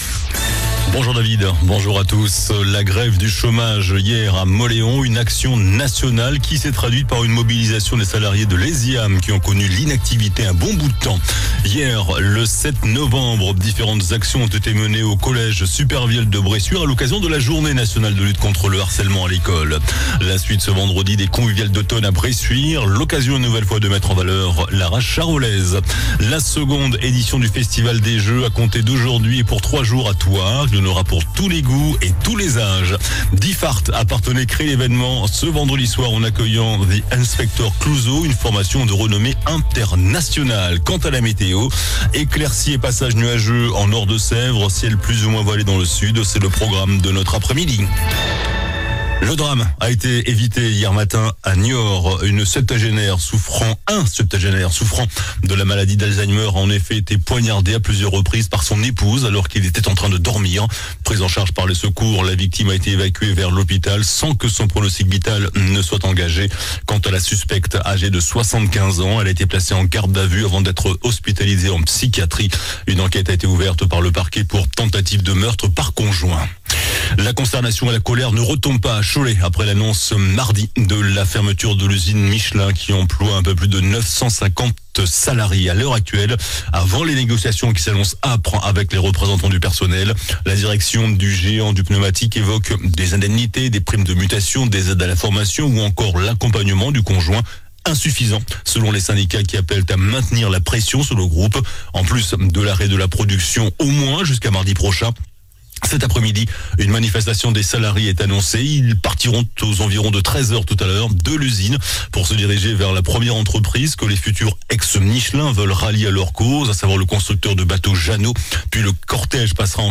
JOURNAL DU VENDREDI 08 NOVEMBRE ( MIDI )